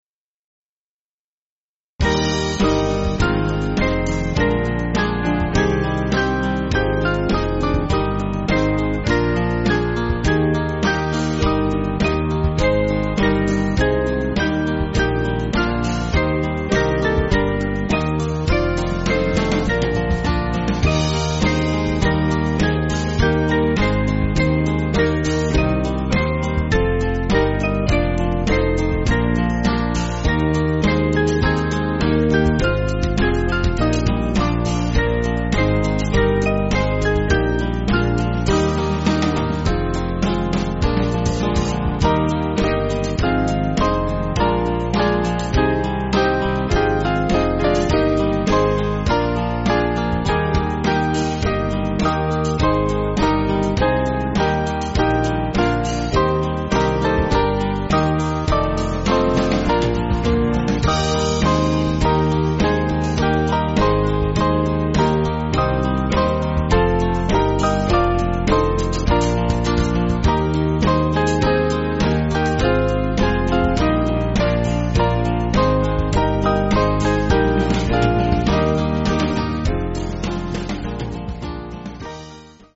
Small Band
(CM)   3/Eb